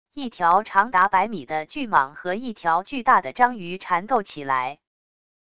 一条长达百米的巨蟒和一条巨大的章鱼缠斗起来wav音频